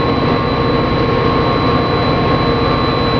F40 OUT of cab Medium Length/Bass
F40editmonoMED1.wav